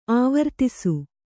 ♪ āvartisu